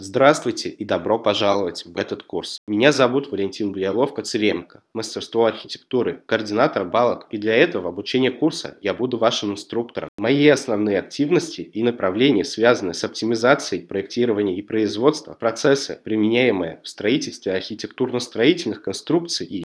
Режим: Видео + озвучка (Русский)
Скачать аудио TTS (WAV)